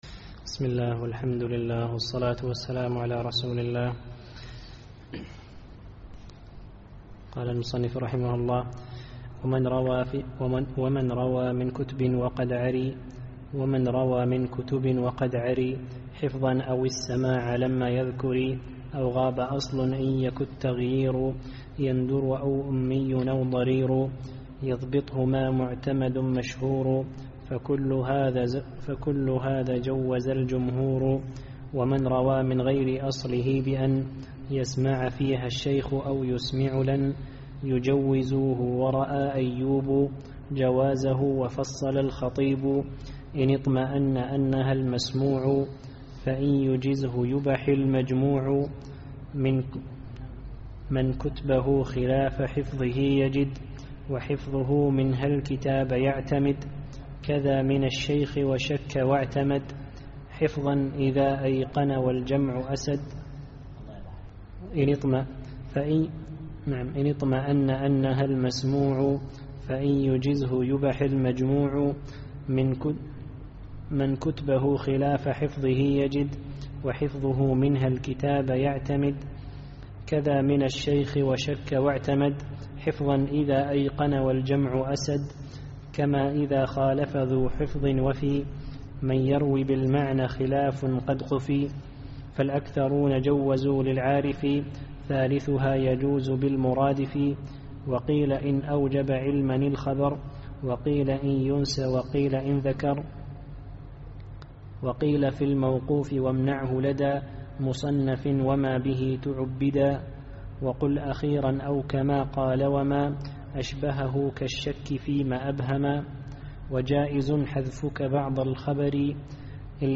الدرس الثاني والعشرون